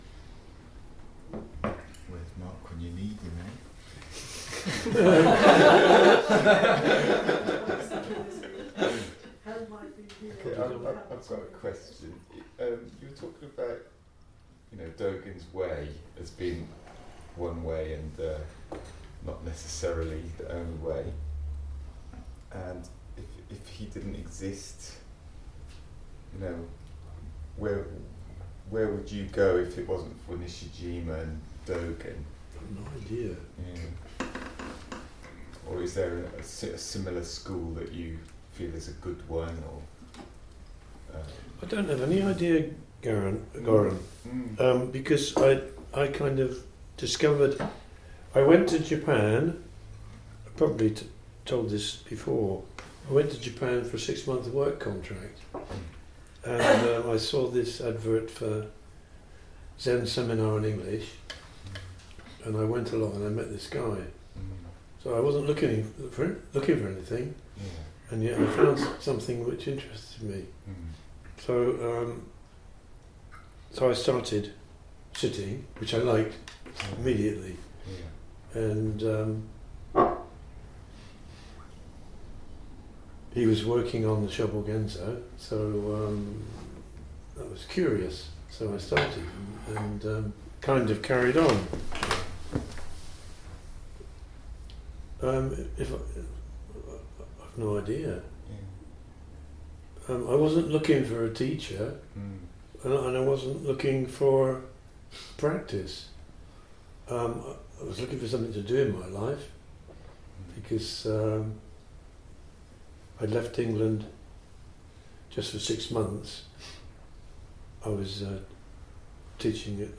Third Talk